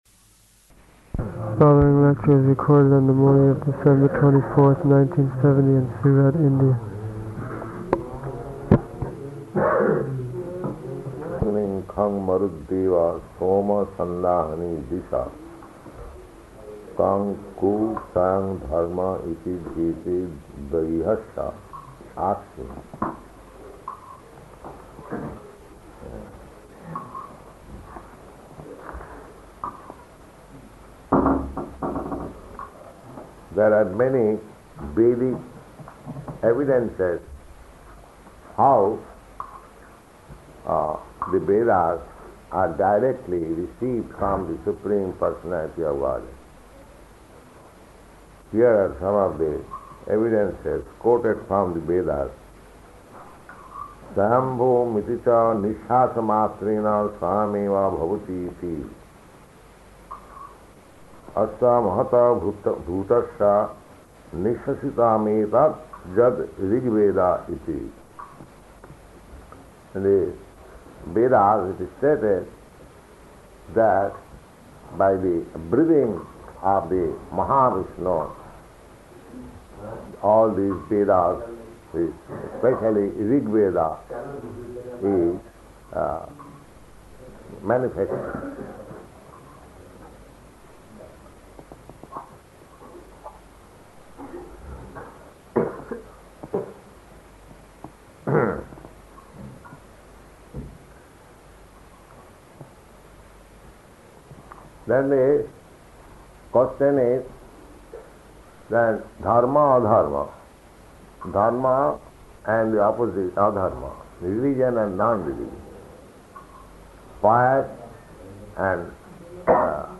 [a few devotees talk at once, indistinct] So his argument was that "Who is the witness?"
Type: Srimad-Bhagavatam
Location: Surat